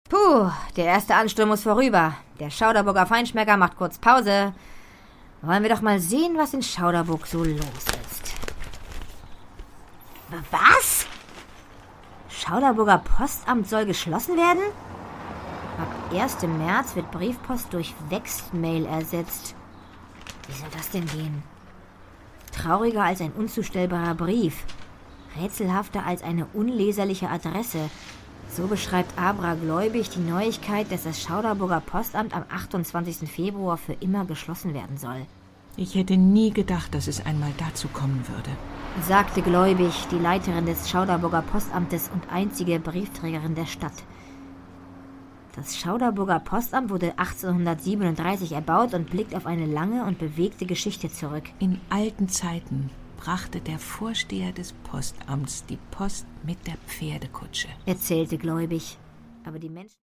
Produkttyp: Hörspiel-Download
Fassung: Hörspiel